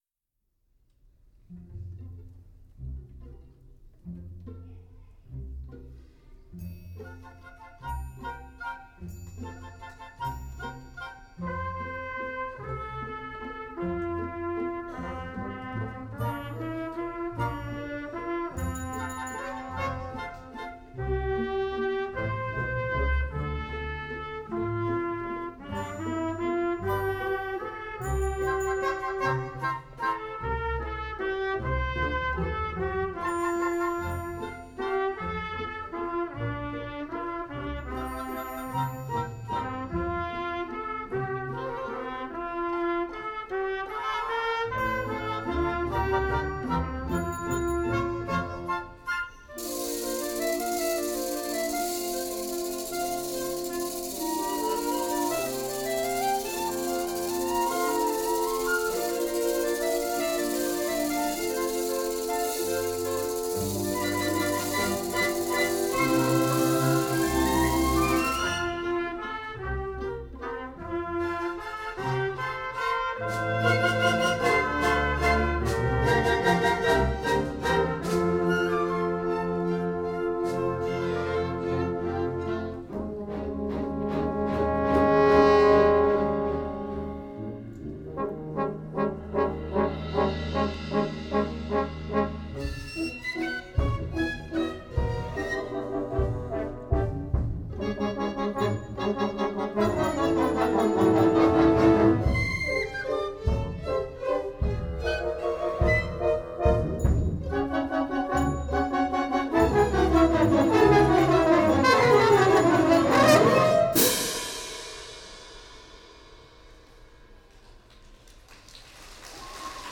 for Orchestra (1997)